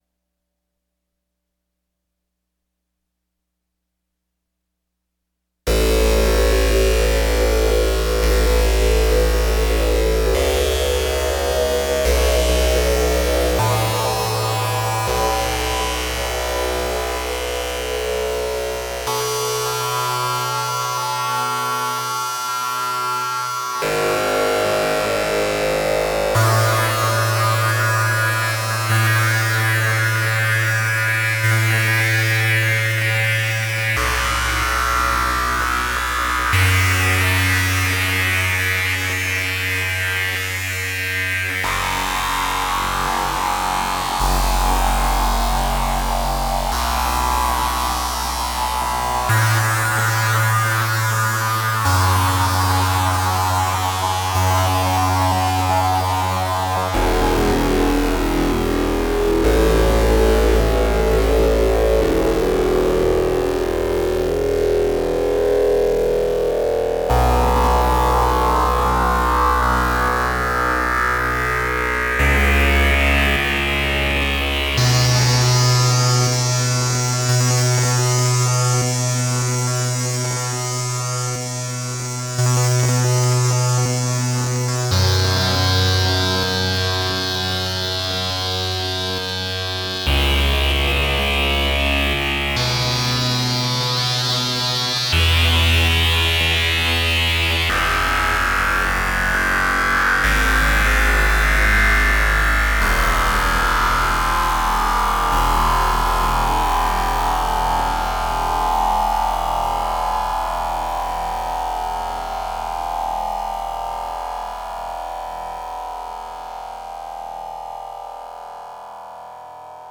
Couple of things with Generate 3, Paradox, and Three Sisters. One is a drone étude. The other pings 3S and Paradox’s PWM for percussion, with a G3 TZFM lead on top. SA Collider and Analog Heat at end of chain.